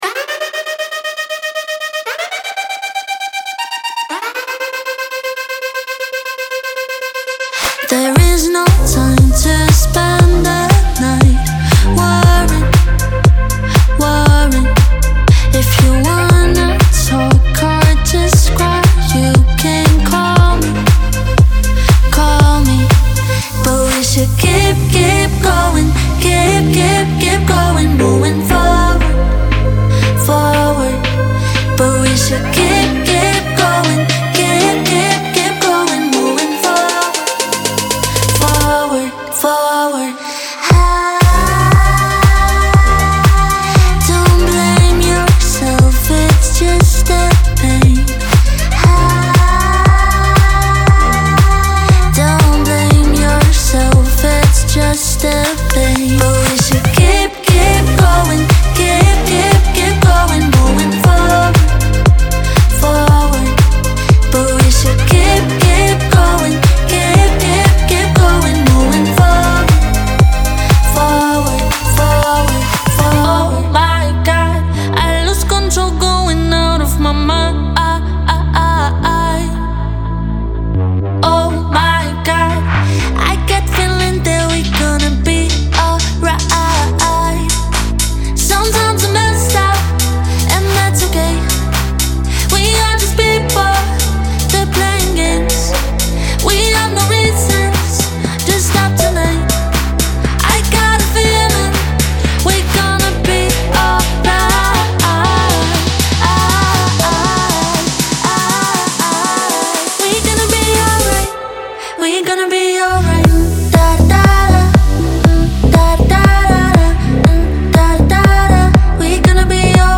デモサウンドはコチラ↓
Genre:Pop